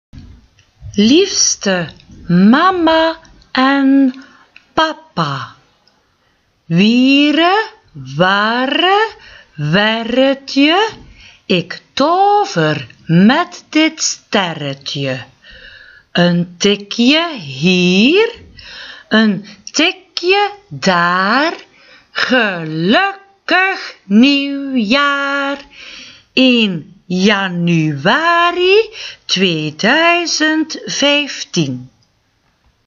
nieuwjaarsversje
Categorie:liedjes en versjes